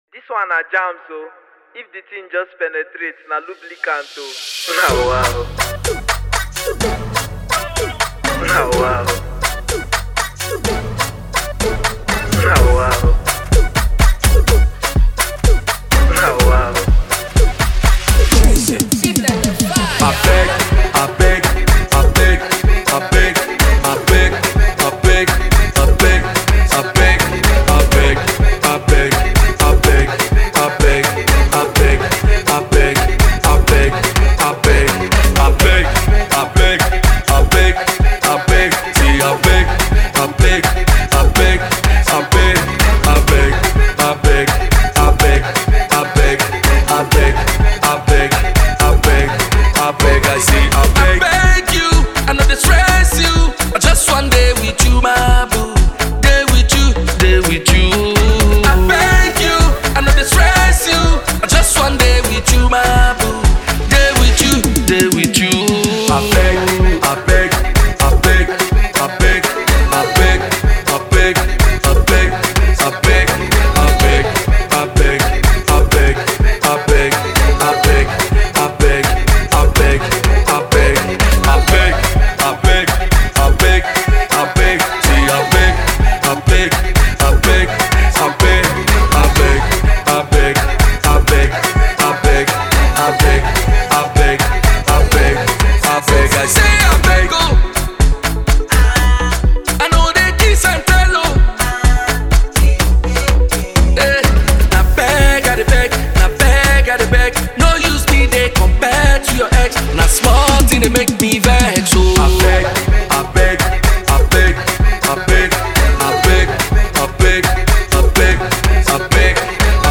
a danceable jam